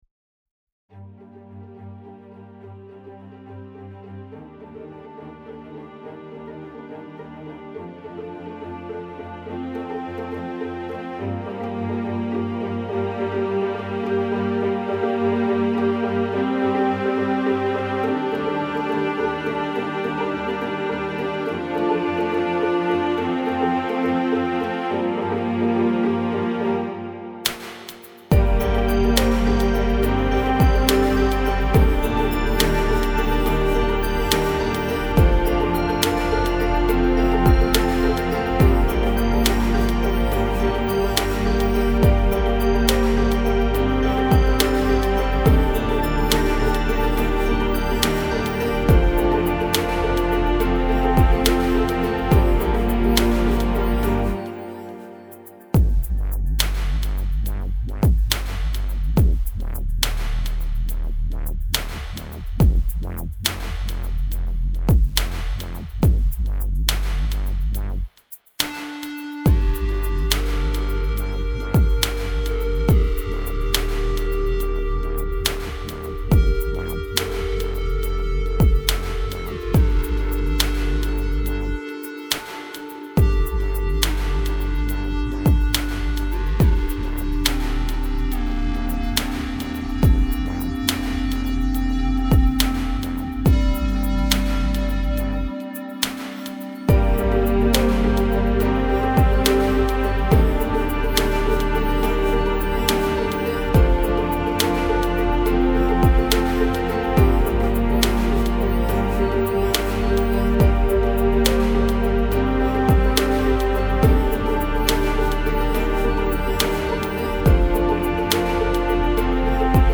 This little waltz is very easy on the ear.